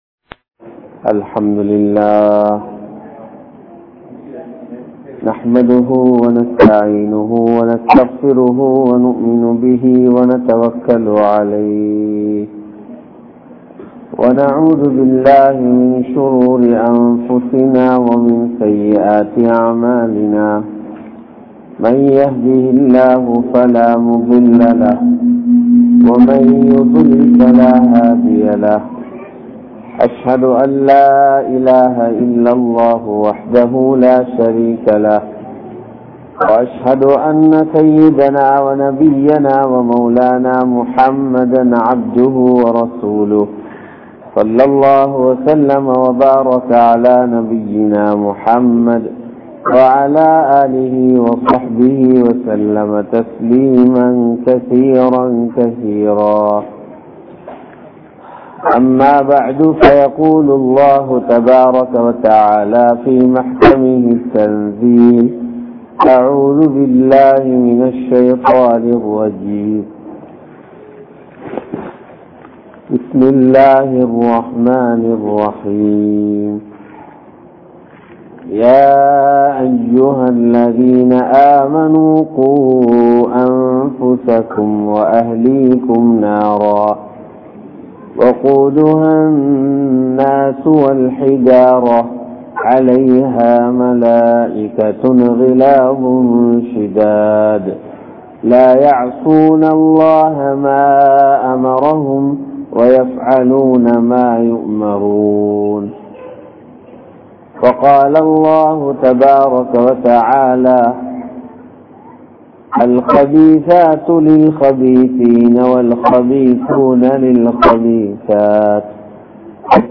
Santhoasam Dheenil Ullathu (சந்தோஷம் தீணில் உள்ளது) | Audio Bayans | All Ceylon Muslim Youth Community | Addalaichenai
Pangaragammana Jumua Masjidh